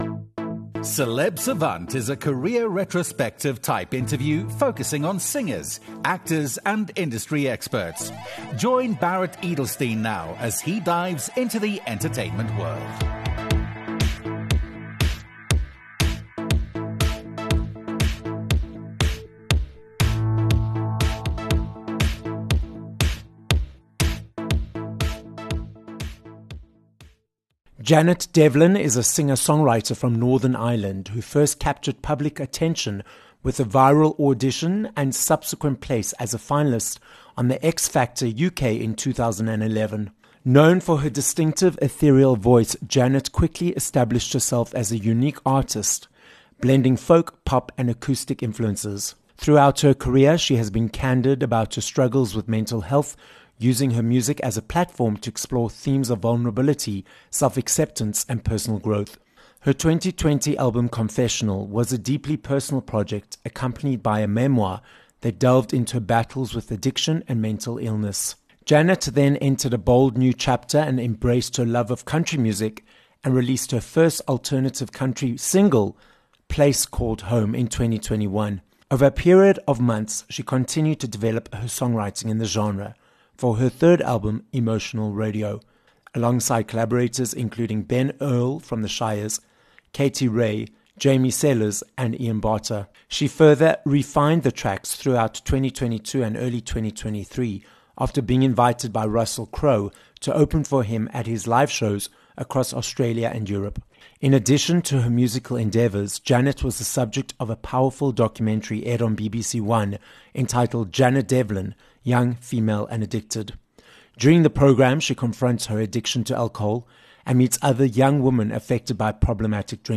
Janet Devlin - an Irish singer and songwriter - joins us on this episode of Celeb Savant. Janet explains her journey as a finalist on X Factor, which she calls 'posh karaoke', why it's important for her to write her own songs, why country music is popular in Ireland and more.